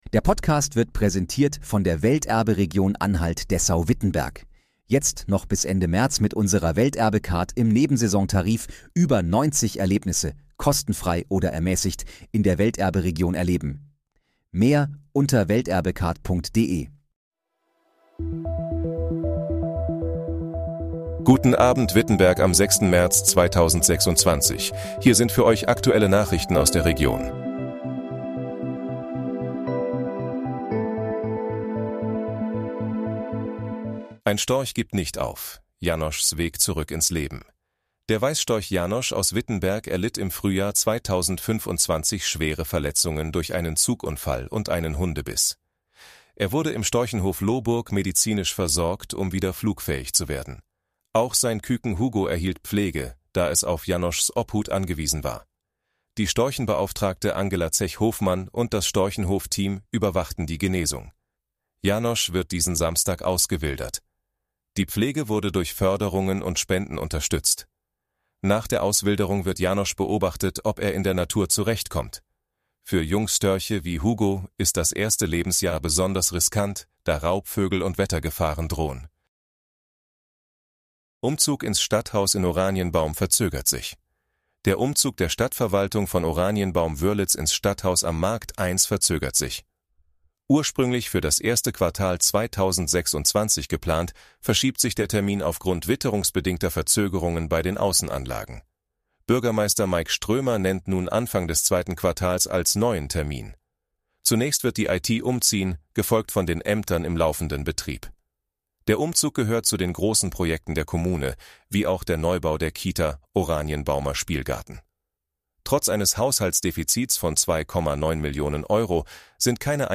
Guten Abend, Wittenberg: Aktuelle Nachrichten vom 06.03.2026, erstellt mit KI-Unterstützung